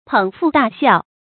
注音：ㄆㄥˇ ㄈㄨˋ ㄉㄚˋ ㄒㄧㄠˋ
捧腹大笑的讀法